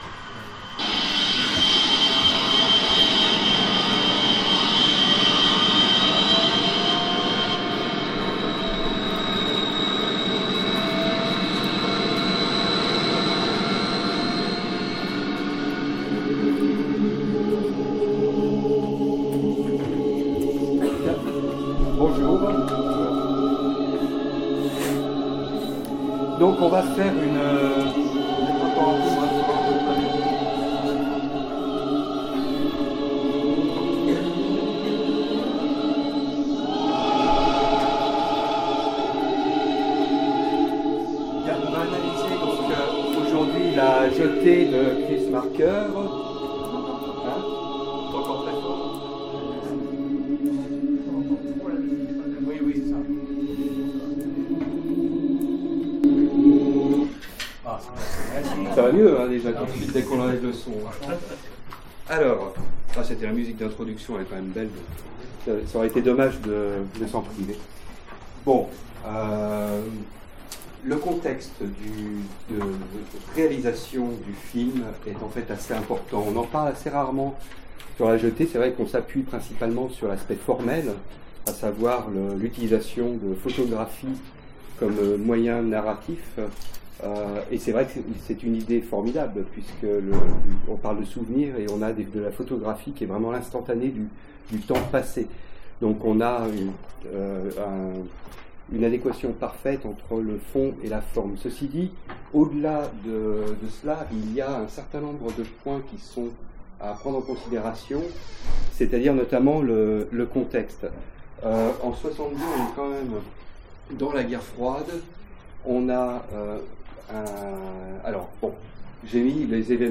Mots-clés Cinéma Conférence Partager cet article